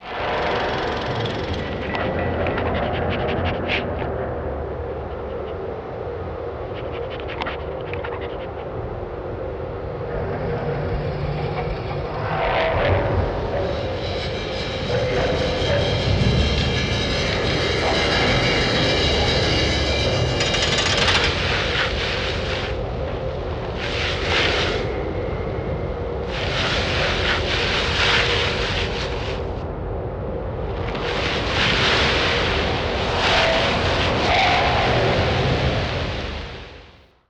I added the sound of turning bearings as a symbol of the “wheels of time.”
At the foot of the hill, I layered low rumbles, granular textures, the sound of rolling stones, and wind to create a rich, multi-layered soundscape.